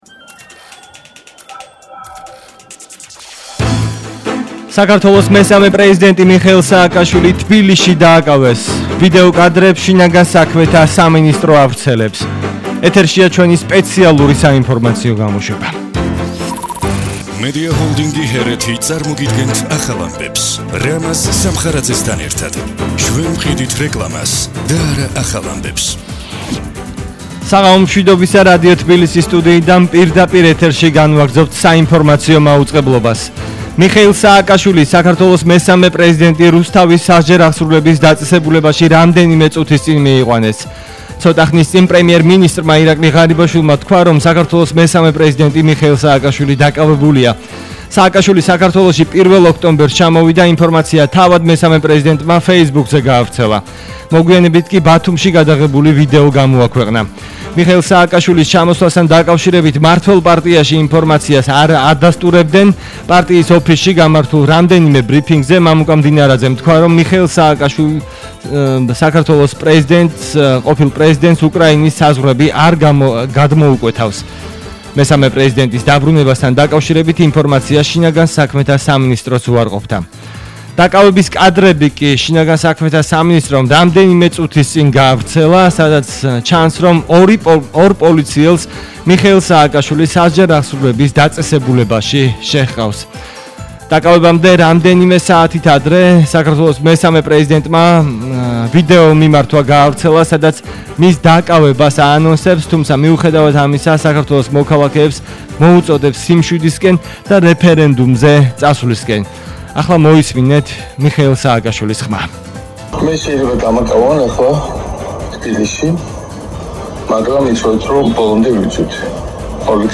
მოუსმინეთ სპეციალურ საინფორმაციო გამოშვებას